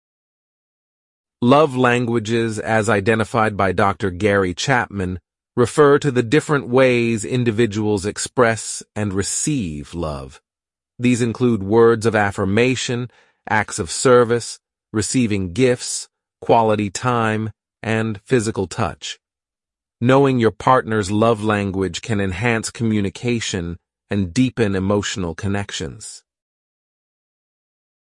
Super Realistic AI Voices For Your News Reading